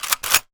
gun_shotgun_cock_02.wav